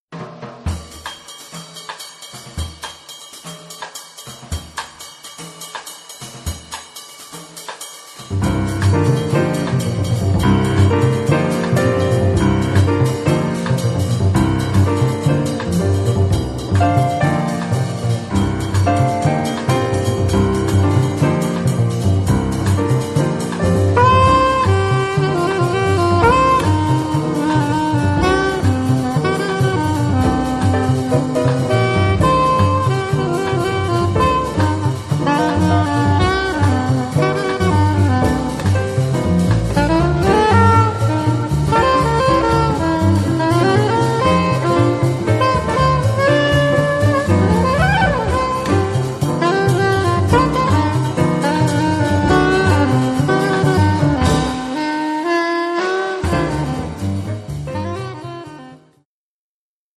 piano e melodica
contrabbasso
batteria
sax tenore e soprano